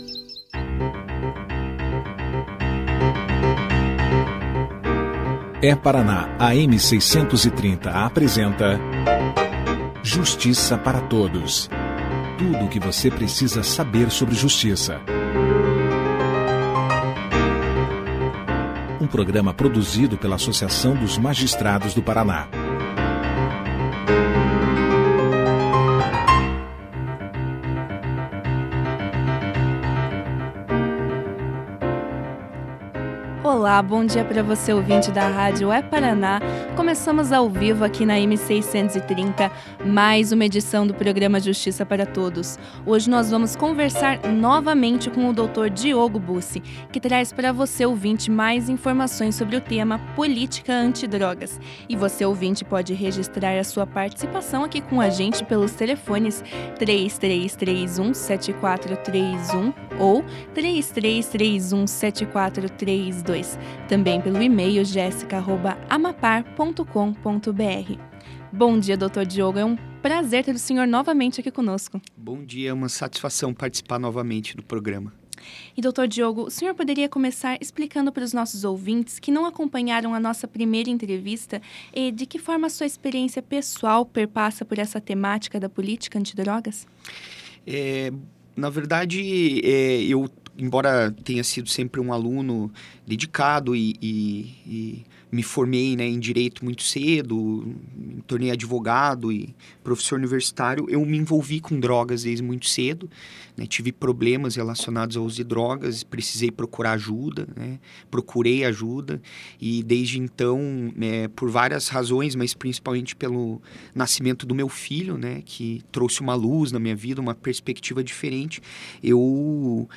O advogado começou a entrevista explicando sobre de que forma a sua experiência pessoal perpassou por essa temática.